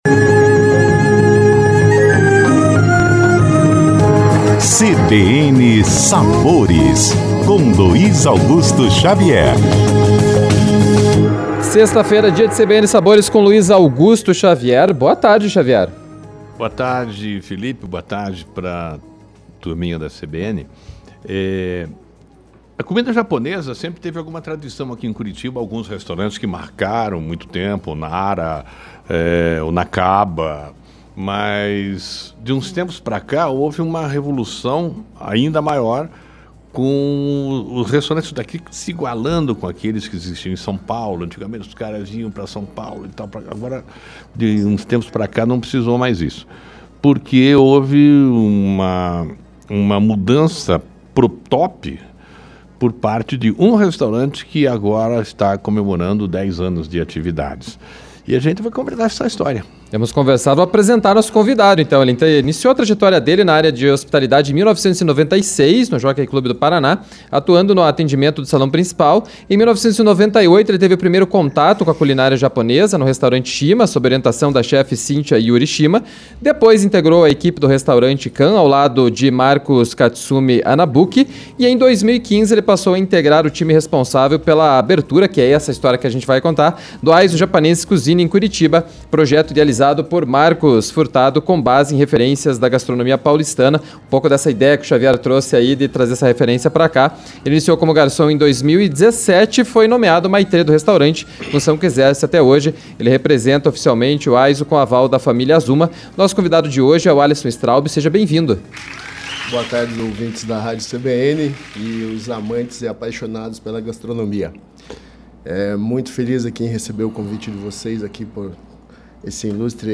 Confira o bate-papo saboroso: